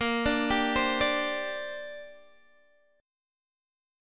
A#6add9 chord